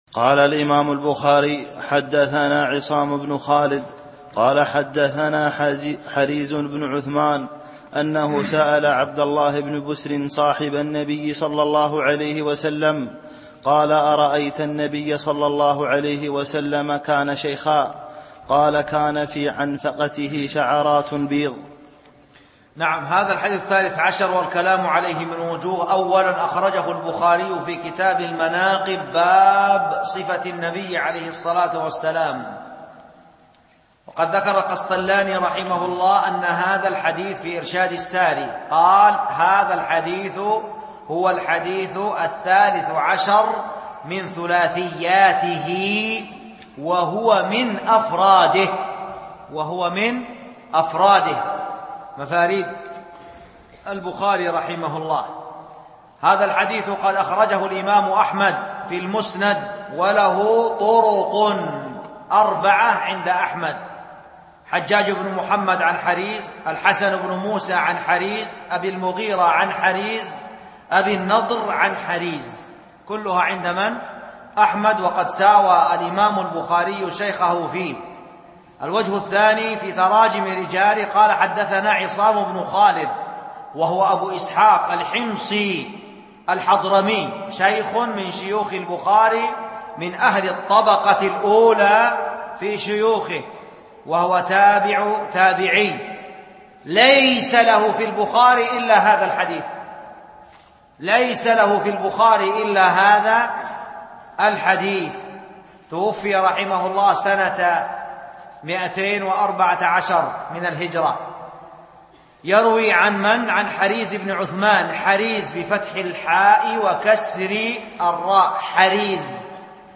التنسيق: MP3 Mono 22kHz 32Kbps (VBR)